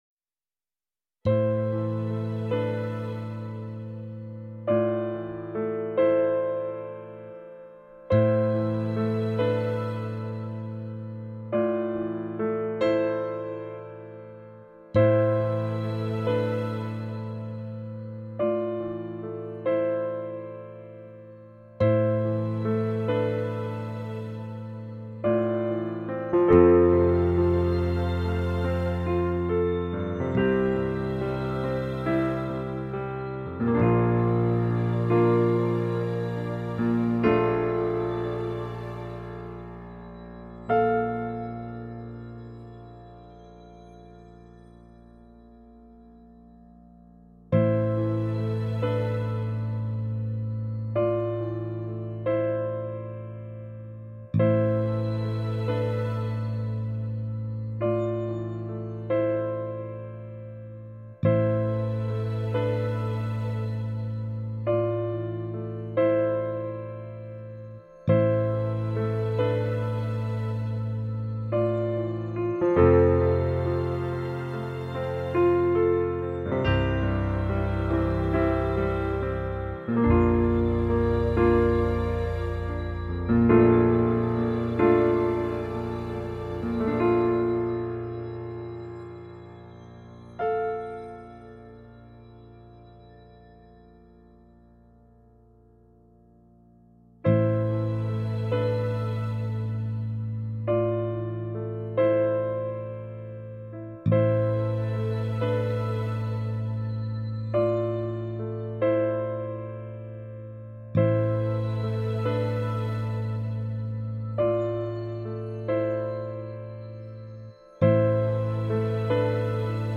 Tässä musiikkiversio ilman sanoja … koko kappale sanoineen julkaistaan myöhemmin.